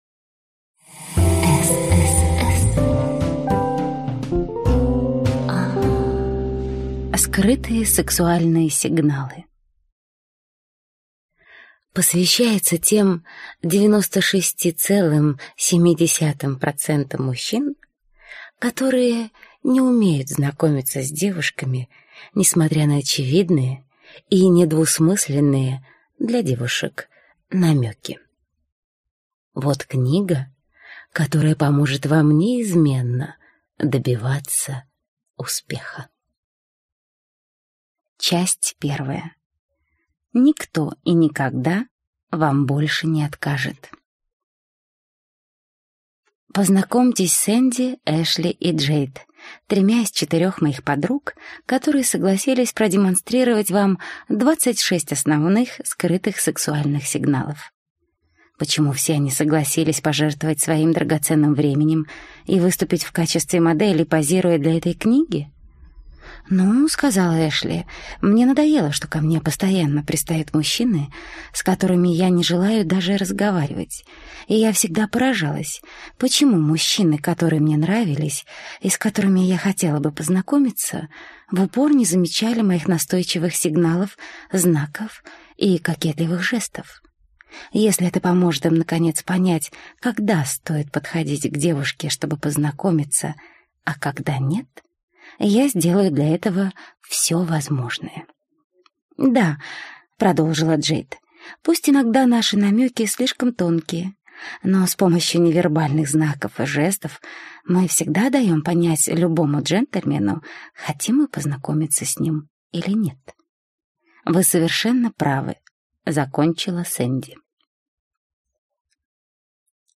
Аудиокнига С.С.С. Скрытые сексуальные сигналы | Библиотека аудиокниг